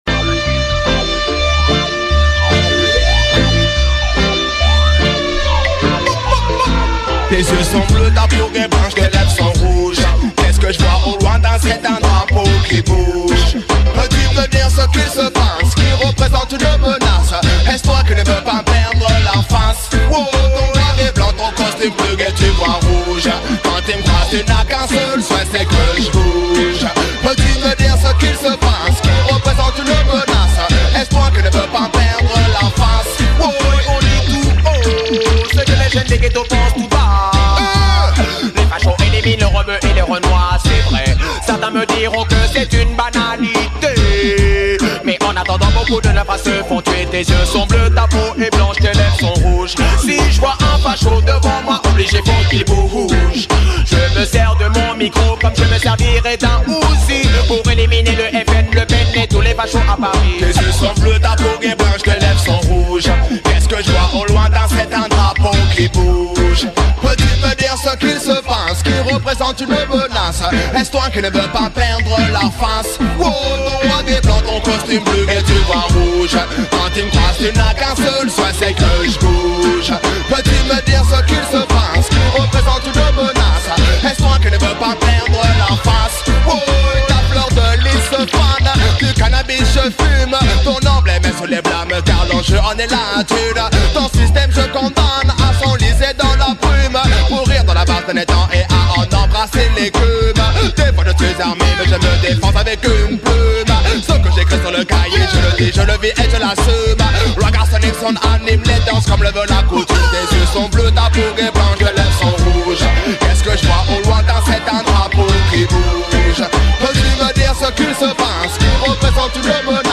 * Lion FreeCaency Radio Show *